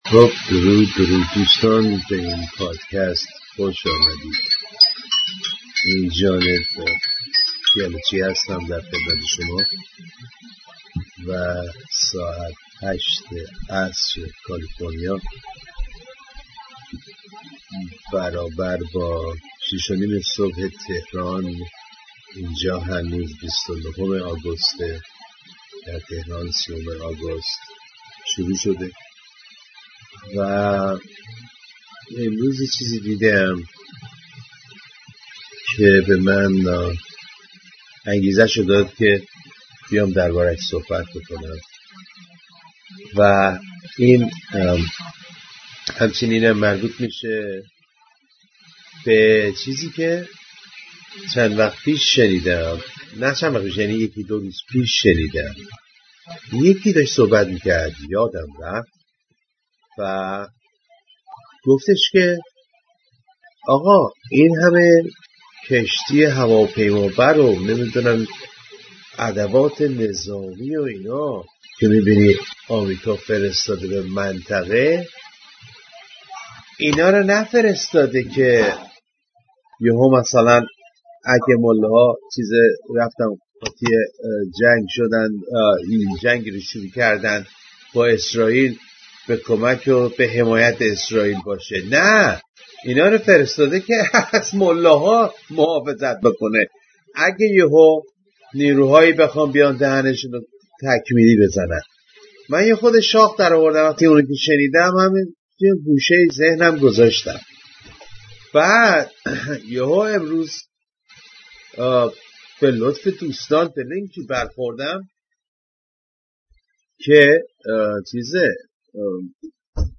شاهد ایستاده‌ایم که به نظر می‌آید عده‌ای می‌خواهند که ملاها بمب را داشته باشند! این پادکست را نقلی و کوتاه قطعش کردیم چون ظاهرا هم اشکال فنی در میان بود و هم چون فی‌البداهه یهو تصمیم گرفتم پادکست بزنم و پادکست بعدیم را زودتر از یکشنبه تصور نمی‌کردم، در فی‌البداهگی و هول یهویی یادم رفت که تیک ضبط کردن را بزنم و اکس/توییتر ضبط نکرد.